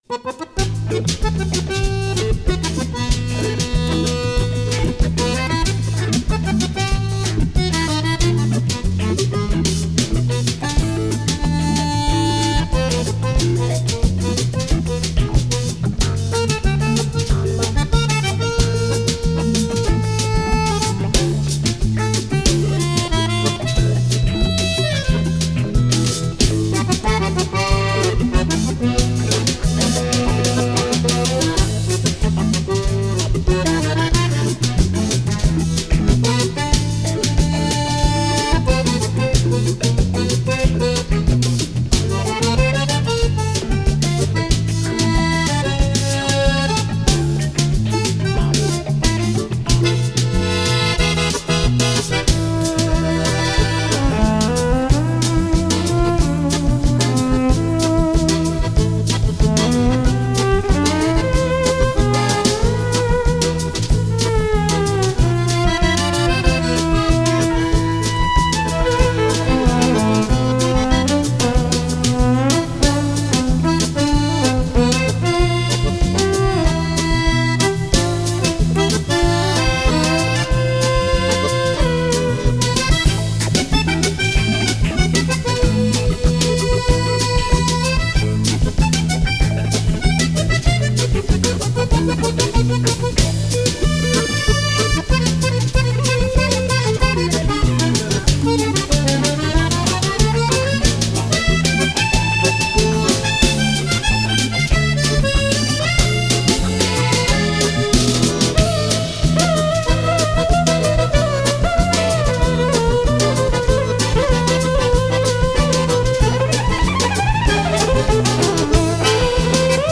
скрипка, вокал.
бас, вокал.
аккордеон, ф-но, вокал.
ударные.